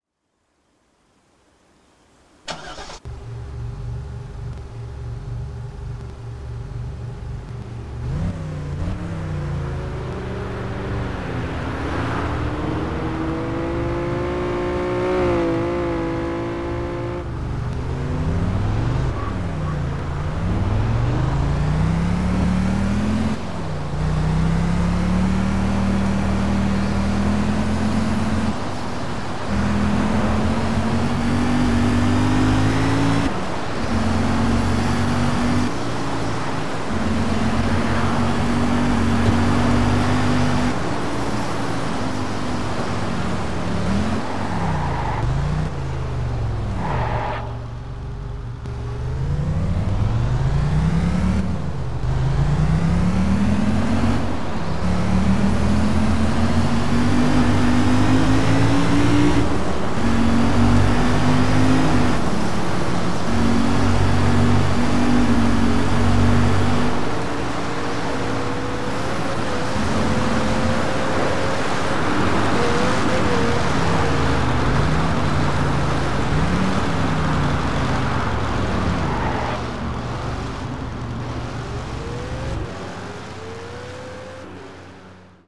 - Chrysler 300 C SRT-8